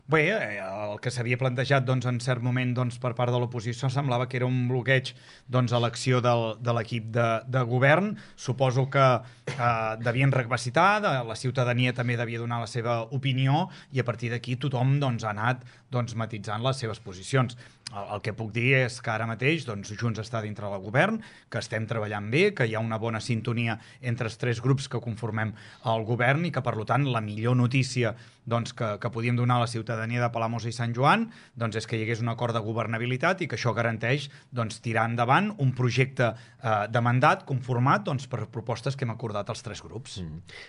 Lluís Puig, alcalde de Palamós i Sant Joan, ha visitat els estudis de Ràdio Capital per explicar algunes de les primeres accions i actuacions futures que es volen impulsar des del govern municipal.